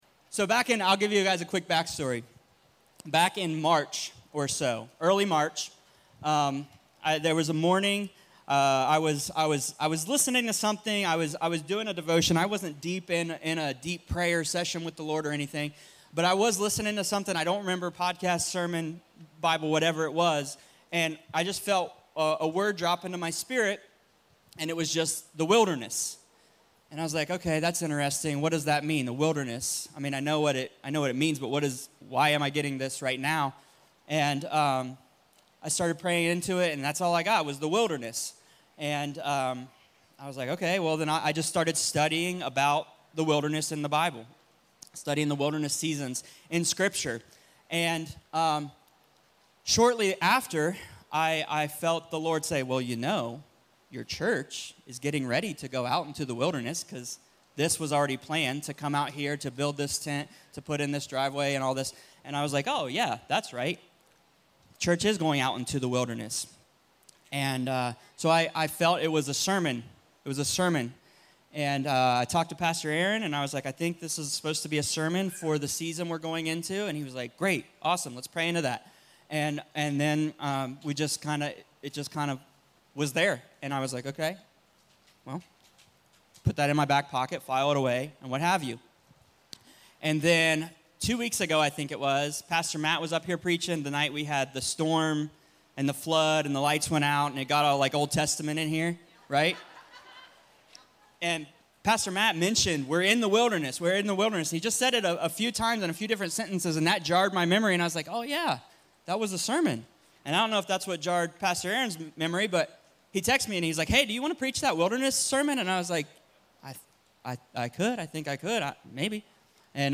Purpose In The Wilderness - Stand Alone Messages ~ Free People Church: AUDIO Sermons Podcast